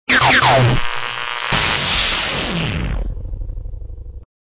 Section#2-Jingles, music logos
All tracks encoded in mp3 audio lo-fi quality.